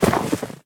Minecraft Version Minecraft Version snapshot Latest Release | Latest Snapshot snapshot / assets / minecraft / sounds / entity / snowman / death3.ogg Compare With Compare With Latest Release | Latest Snapshot